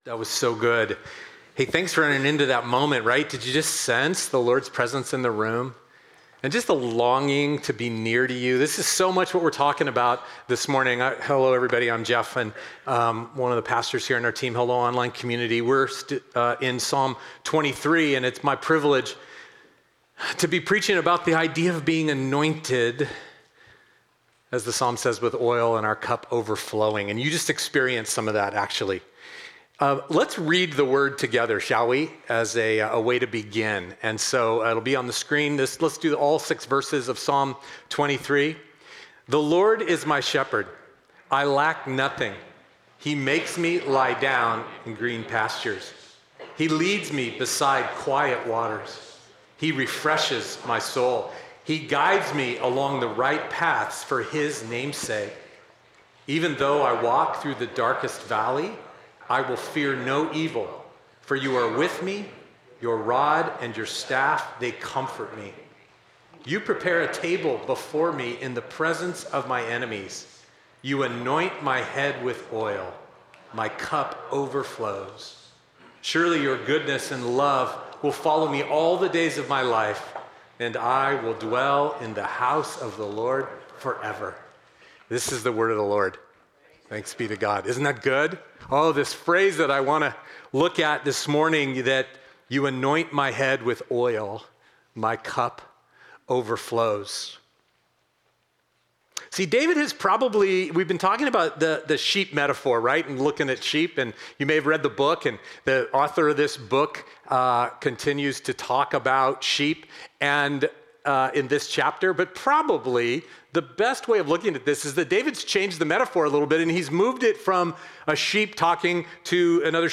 Sunday messages and other streaming media from MCC.